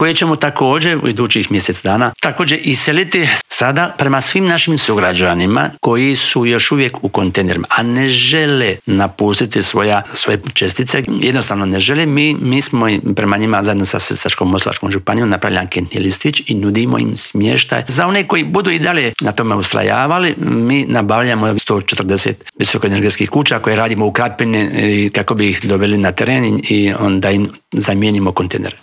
Odgovore smo zatražili u Intervjuu tjedna Media servisa od potpredsjednika Vlade i ministra prostornoga uređenja, graditeljstva i državne imovine Branka Bačića.